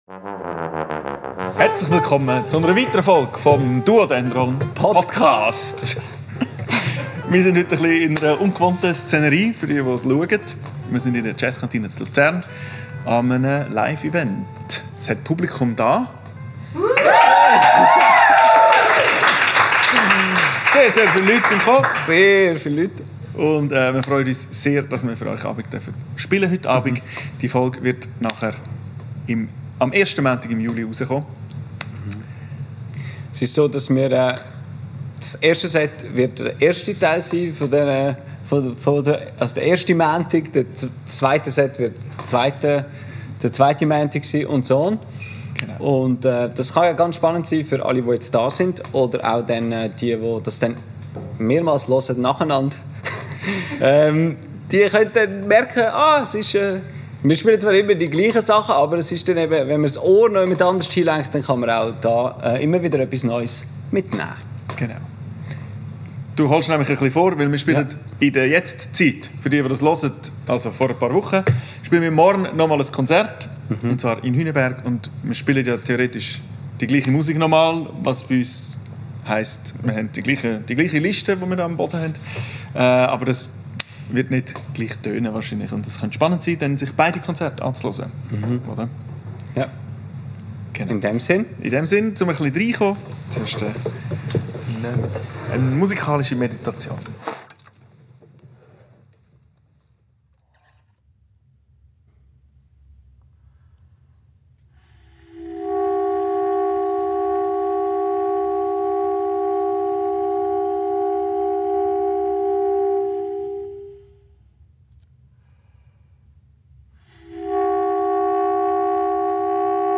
Der erste Teil unserer Live-Podcasts! Aus dem dunklen Keller der Jazzkantine präsentieren wir euch das erste Set unserer Mini-Tournee 2025. Aufgenommen am 26.06.25 in der Jazzkantine Luzern